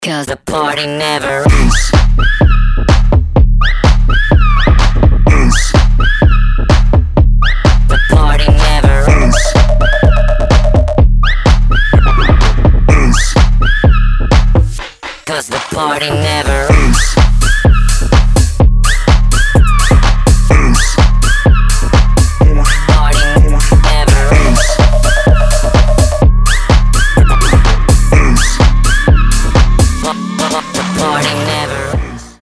• Качество: 320, Stereo
ритмичные
EDM
Brazilian bass
electro house
jungle terror
электронный голос
moombahcore
Стиль: brazilian bass